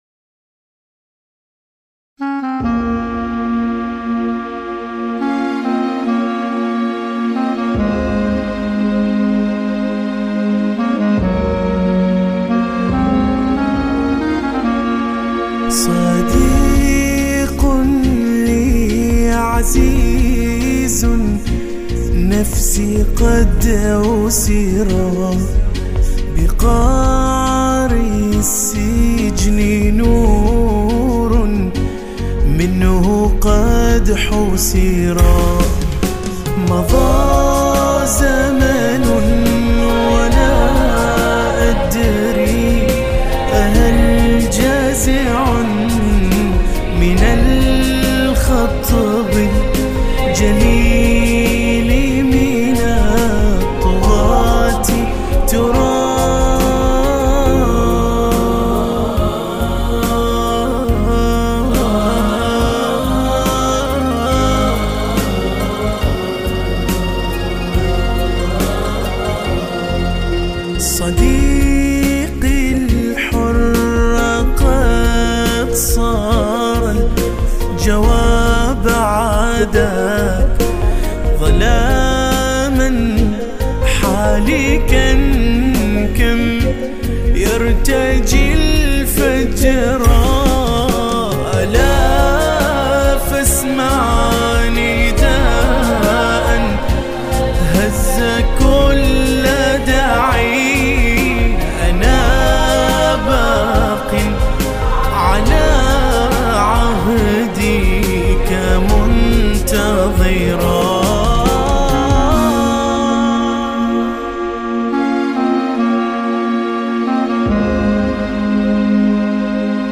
أناشيد بحرينية نشيد